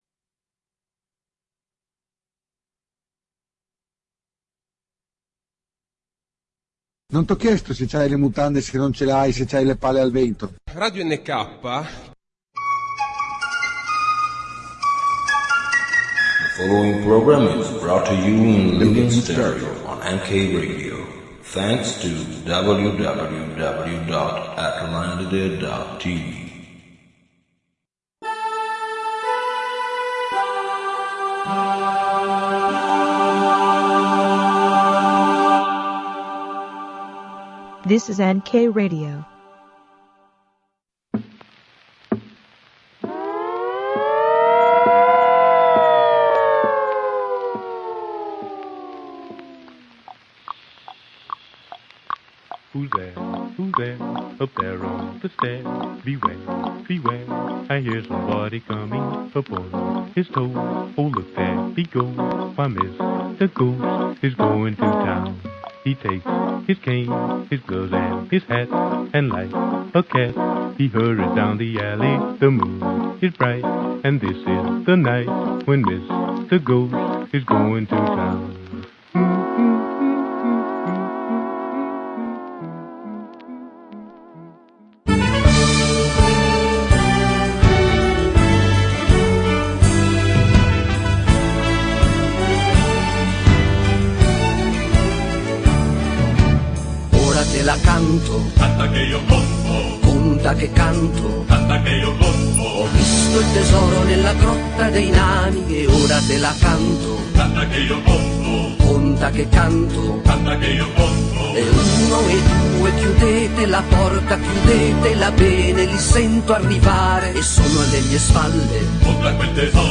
Allegria, funambolismo, gioia di vivere a paccate.